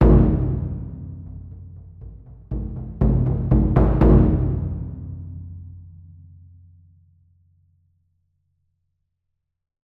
その響きを再現するために、TAIKO THUNDERはスタジオではなくホールでサンプリング収録を行いました。
• StageB：ステージ後方のマイクポジションのサウンド（ステレオ）です。